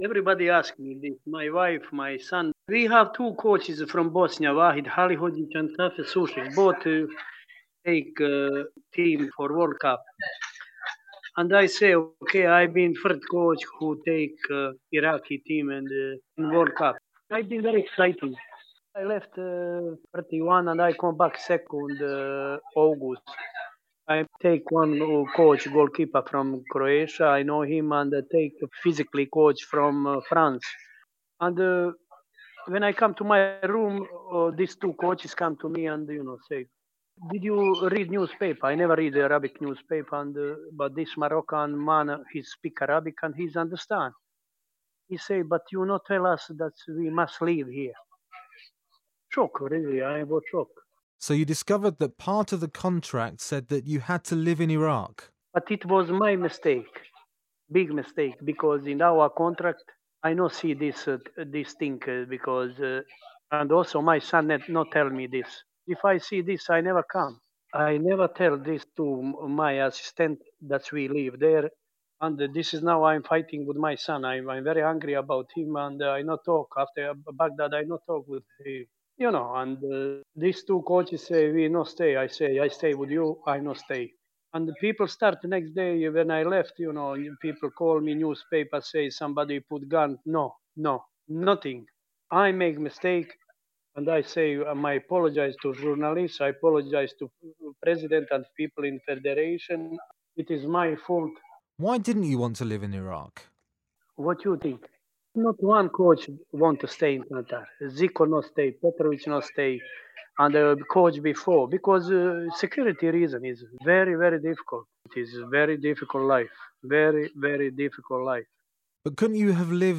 INTERVIEW: Dzemal Hadziabdic on why he changed his mind about the Iraq job after 2 days